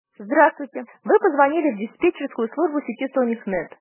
ivr-sonic-greet.mp3